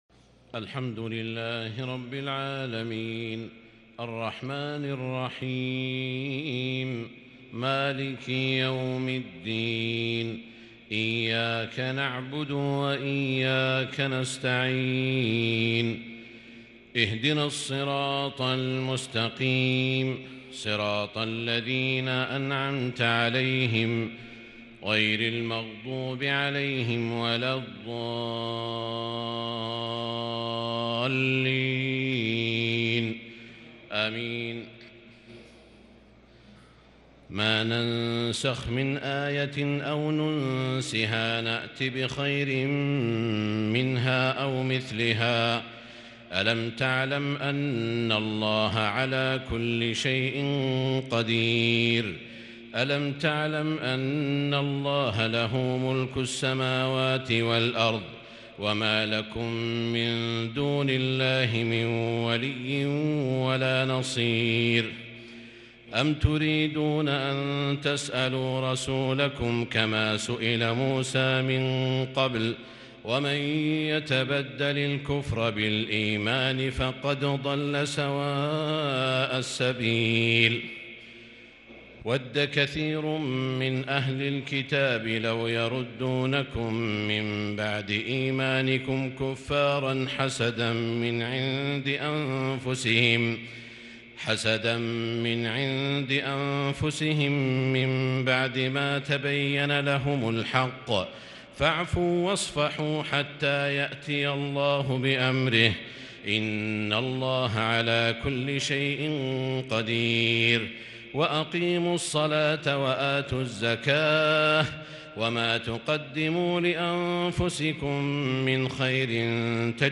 تراويح الليلة الثانية رمضان 1442 من سورة البقرة (١٠٦ - ١٦٧) Taraweeh 2st night Ramadan 1442H > تراويح الحرم المكي عام 1442 🕋 > التراويح - تلاوات الحرمين